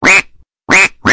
Enten Quaken klingelton kostenlos
Kategorien: Tierstimmen
Enten-Quaken.mp3